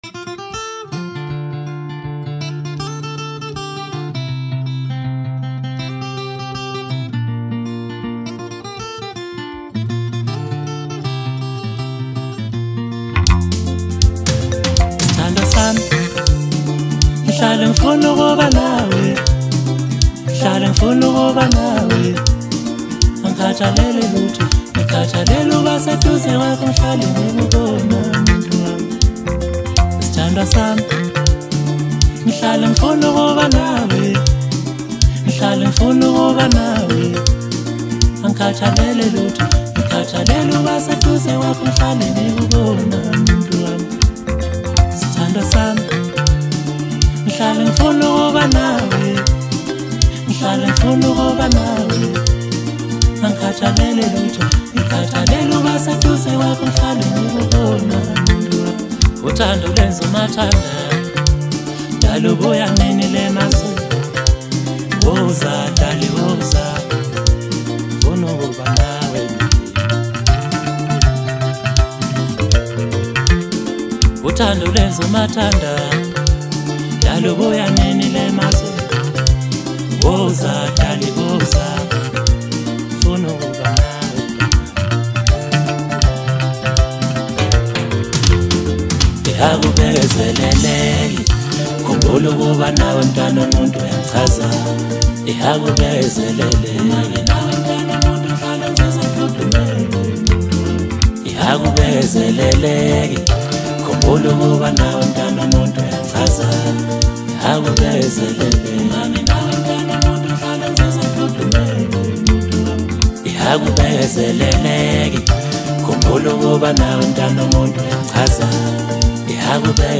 Maskandi, Gqom